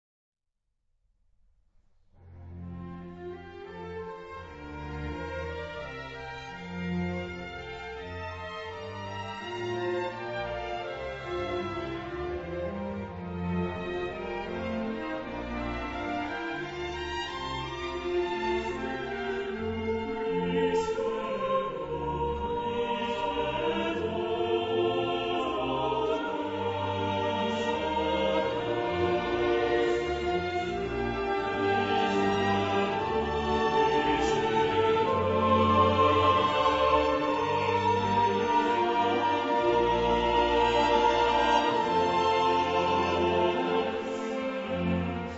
Genre-Style-Forme : Cantate de chorals ; Sacré
Type de choeur : SATB  (4 voix mixtes )
Instrumentation : Ensemble instrumental  (7 partie(s) instrumentale(s))
Instruments : Cordes (4) ; Flûte (1) ad libitum ; Hautbois (ad lib) ; Clarinette (ad lib)
Tonalité : fa majeur
interprété par Kammerchor Stuttgart + Stuttgarter Kammerorchester dirigé par Frieder Bernius